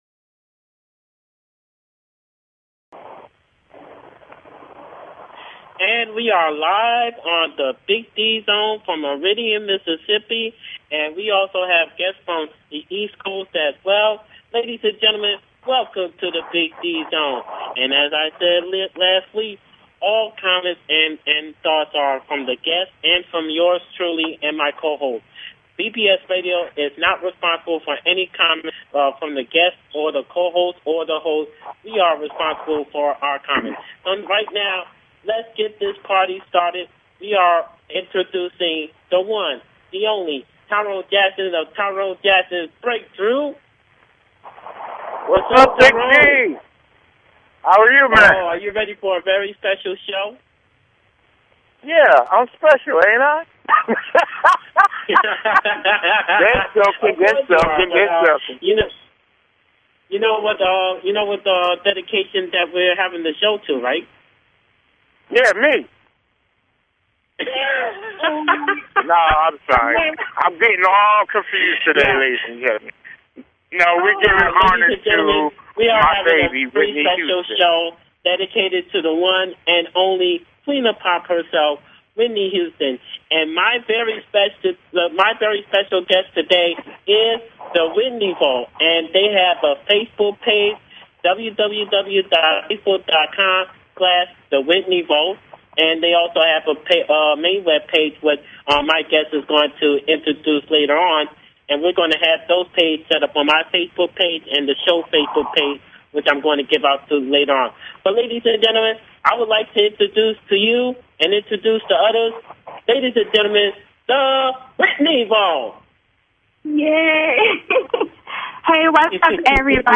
Talk Show Episode
The show will feature artists from R&B, RA, HIP HOPGOSPEL, POETRY, ROCK, AND MAY BE MORE!